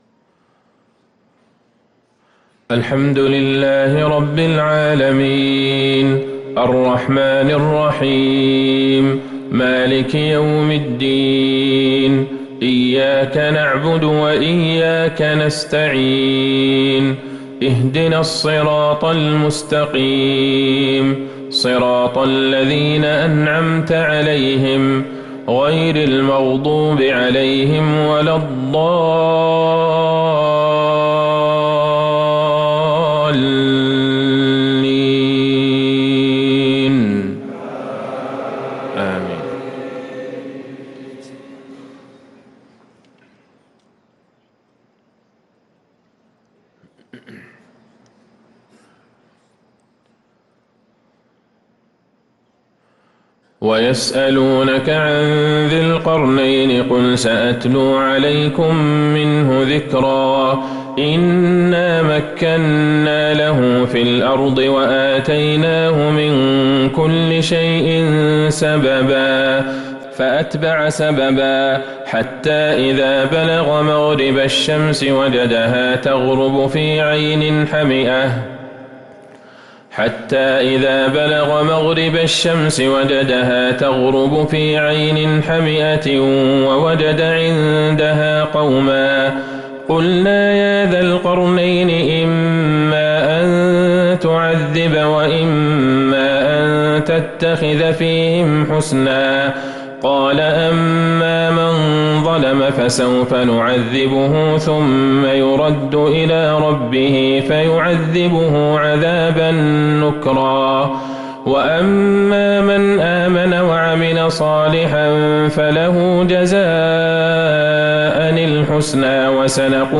صلاة الفجر للقارئ عبدالله البعيجان 17 ذو القعدة 1444 هـ